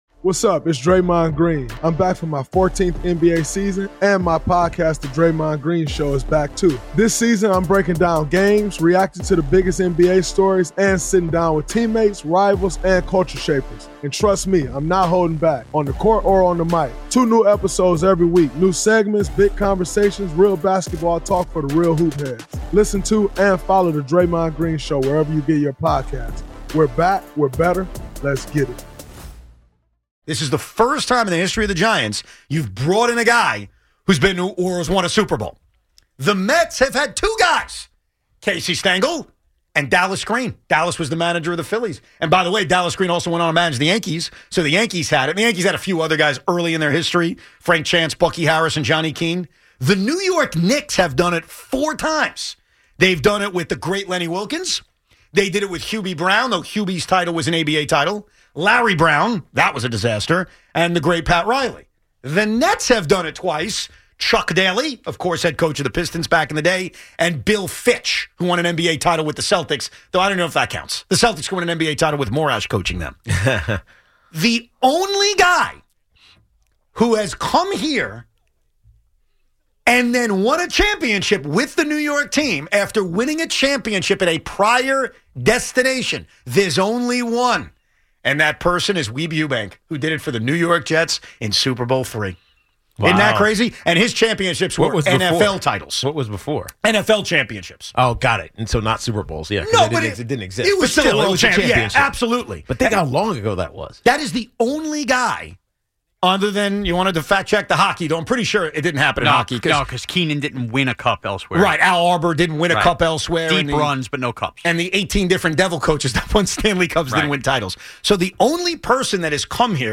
They land on Weeb Ewbank as the lone example of someone who won before coming to a New York team and then won again here, framing just how unique the John Harbaugh moment is for the Giants. Then the phones roll with Harbaugh hype, expectations for Year 1, and early buzz about what his staff could look like, including chatter around Todd Monken.